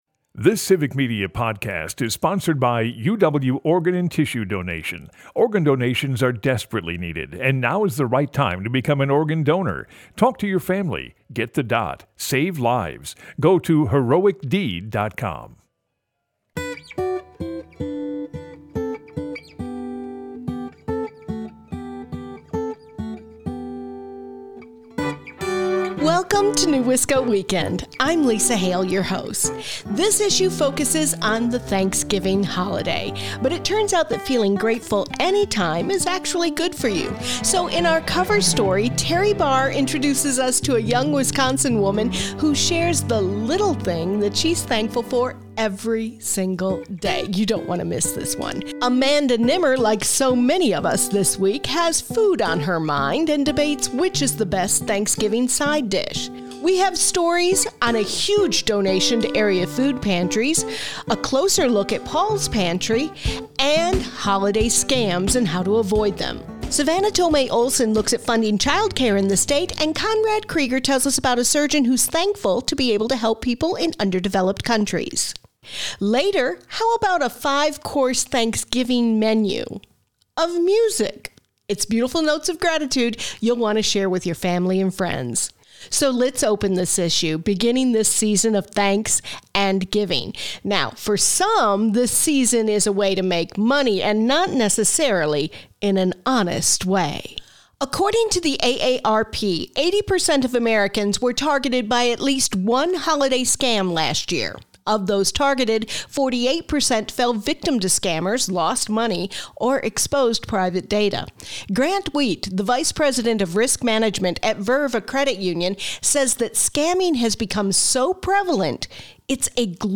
And music that sets the listener up for gratitude.